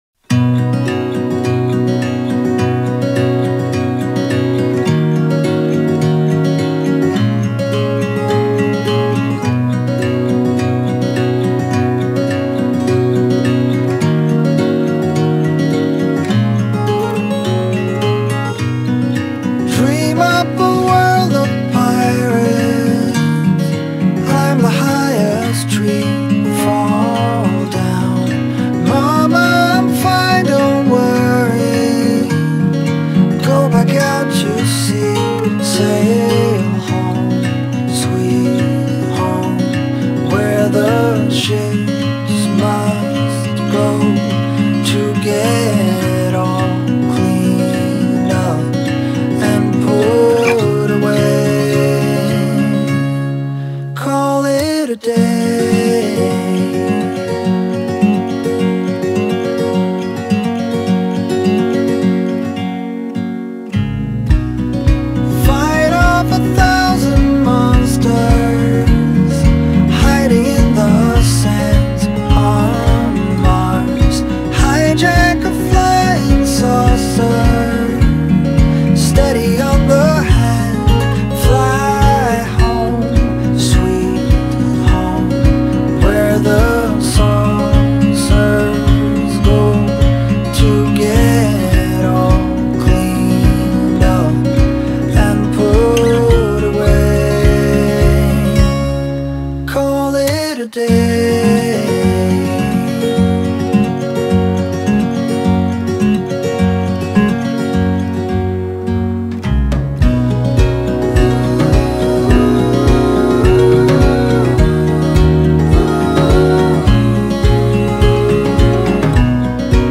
Хорошая романтичная баллада